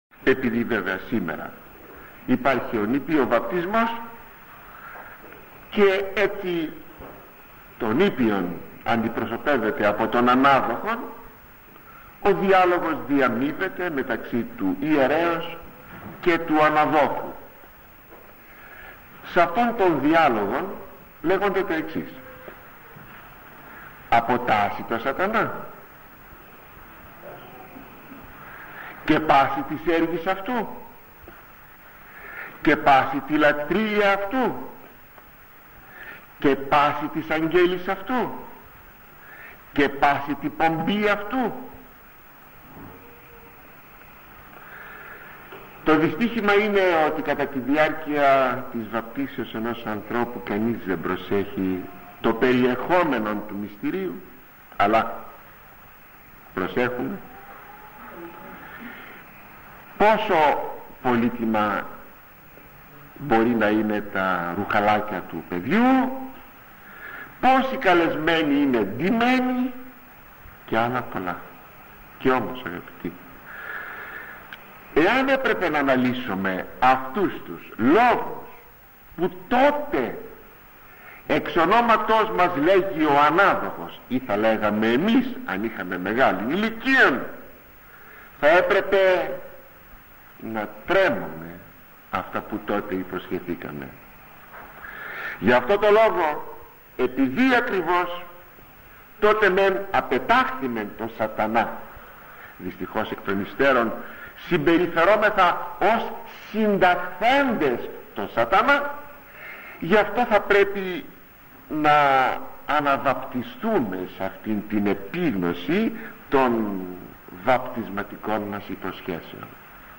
Ζητούμε εκ των προτέρων συγνώμη για την κακή ποιότητα του ήχου, αλλά νομίζουμε πως προέχει η αξία των λεγομένων, σ’ αυτές τις παλαιές ηχογραφήσεις.